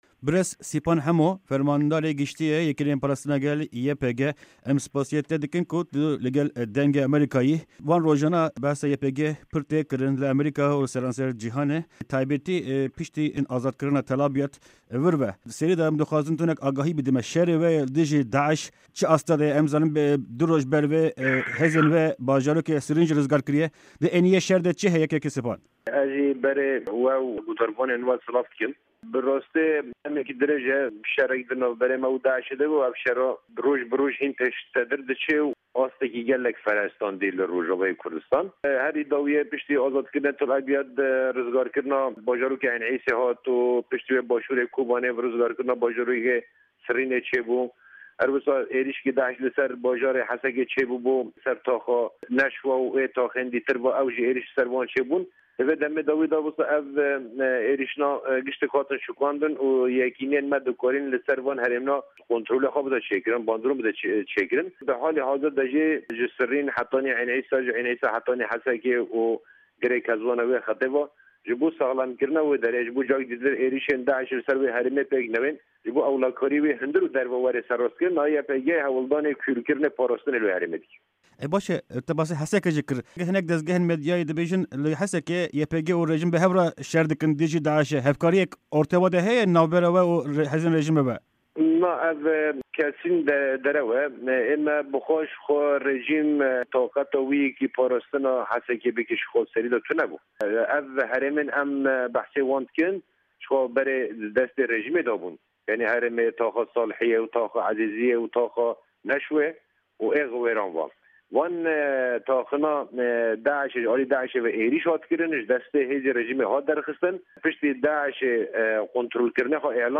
Sipan_Hemo_Interview_8_3_15_RR_1